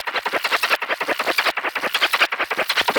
Index of /musicradar/rhythmic-inspiration-samples/80bpm
RI_ArpegiFex_80-04.wav